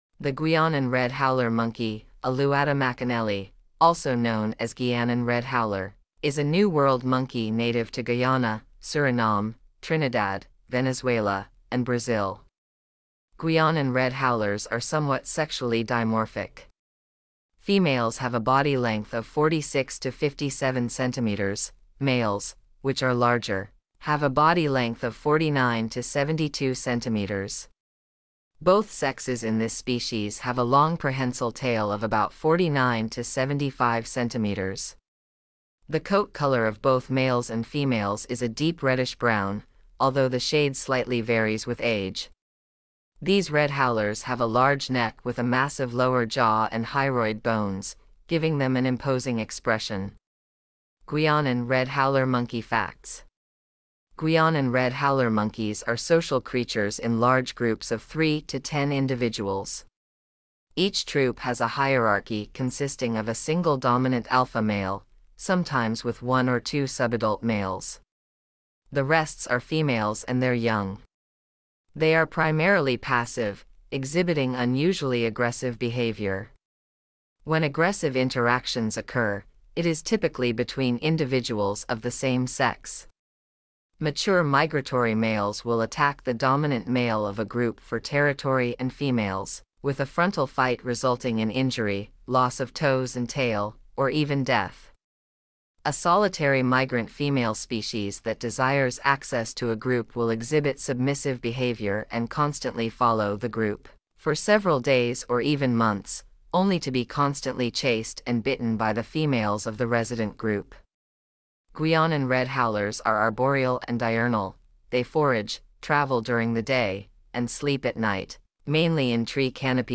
Guyanan Red Howler Monkey
Guyanan-red-howler-monkey.mp3